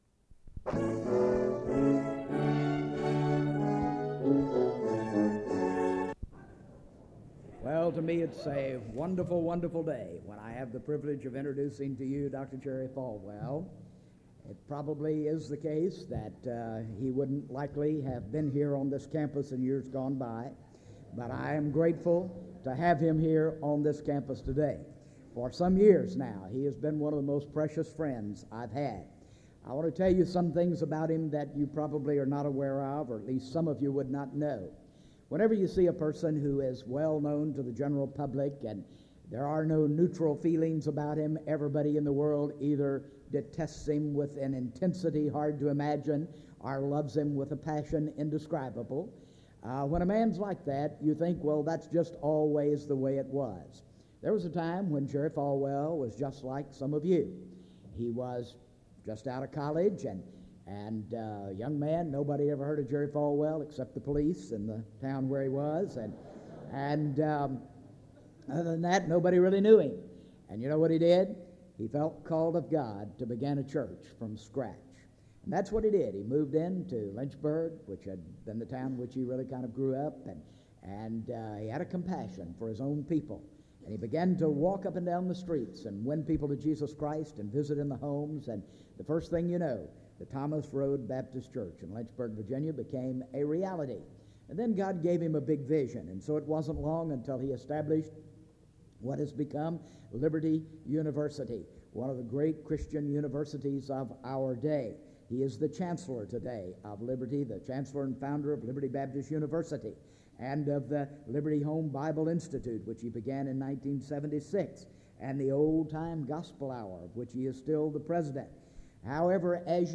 SEBTS Chapel - Jerry Falwell March 18, 1997
Dans Collection: SEBTS Chapel and Special Event Recordings SEBTS Chapel and Special Event Recordings - 1990s La vignette Titre Date de téléchargement Visibilité actes SEBTS_Chapel_Jerry_Falwell_1997-03-18.wav 2026-02-12 Télécharger